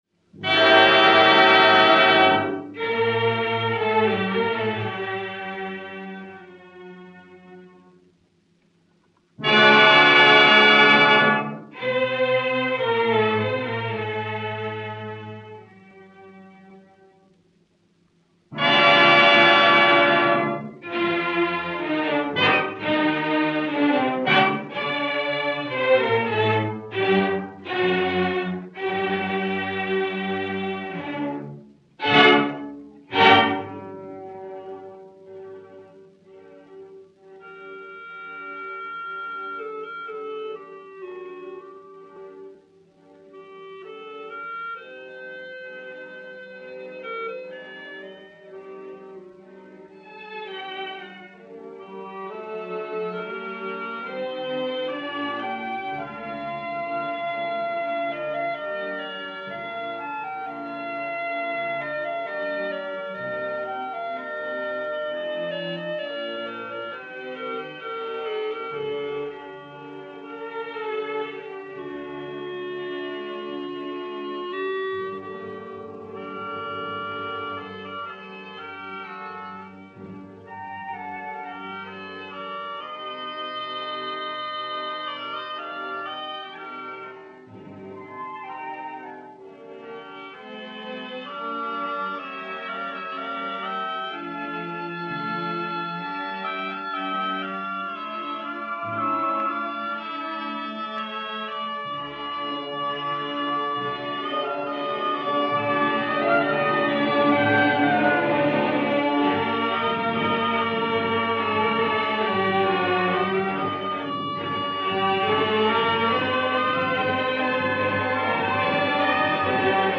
vibrante, nerveuse et colorée
Ouverture